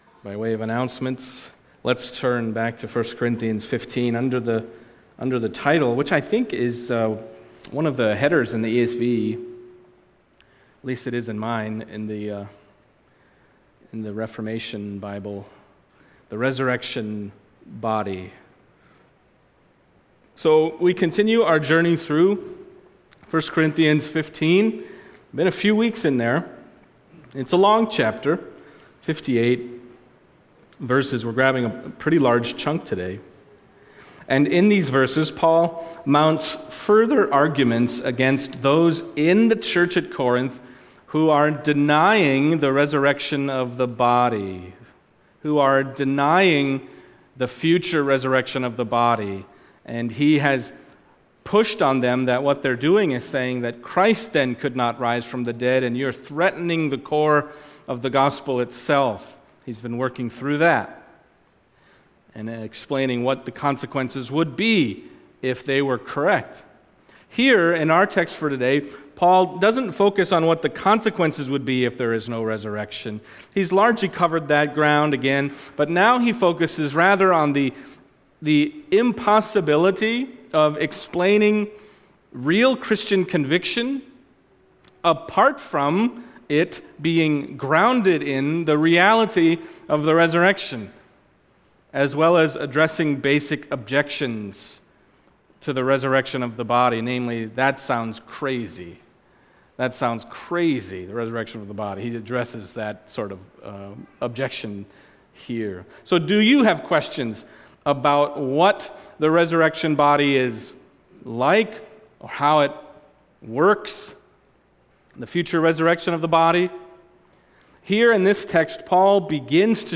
1 Corinthians 15:29-50 Service Type: Sunday Morning 1 Corinthians 15:29-50 « Christ the Firstfruits…